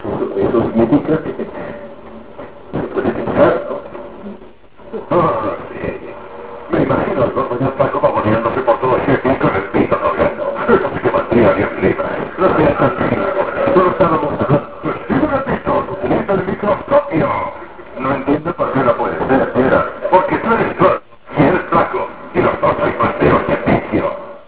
VOCES DE LA PELÍCULA